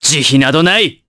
Roi-Vox_Skill2_jp.wav